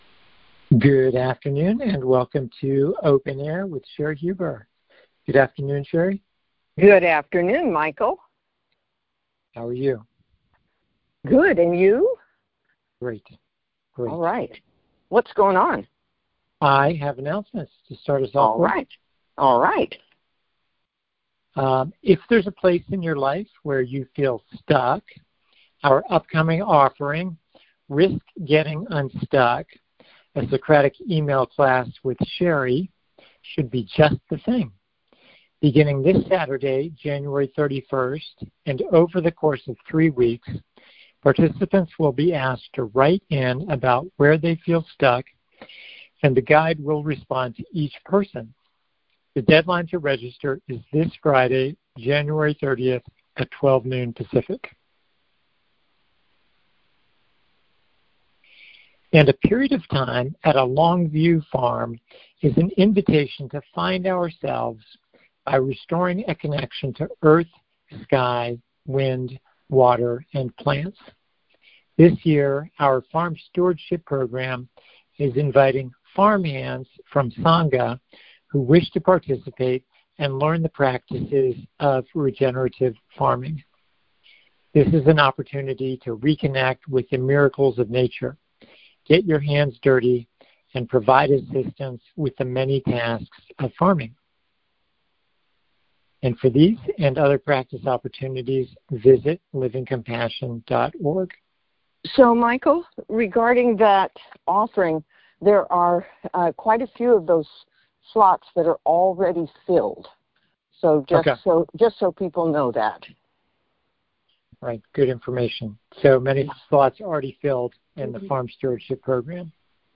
on the air to facilitate a conversation to support the practice of conscious, compassionate awareness.